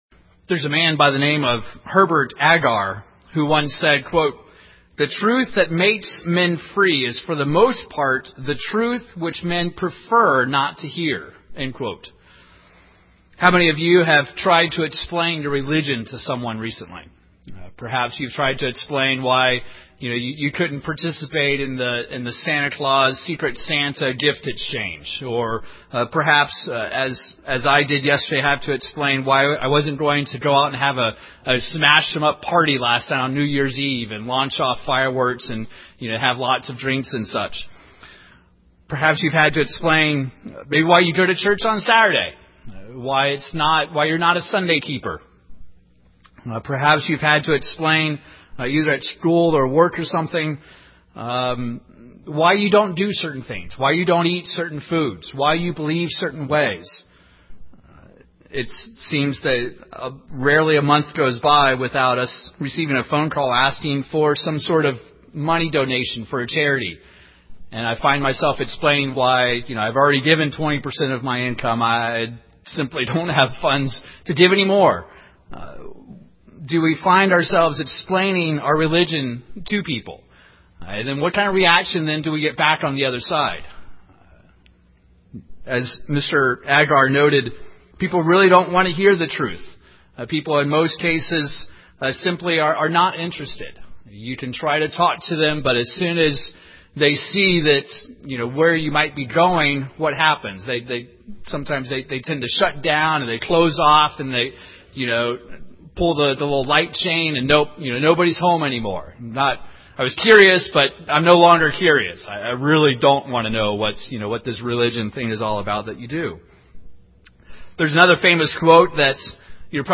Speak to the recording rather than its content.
Given in Wichita, KS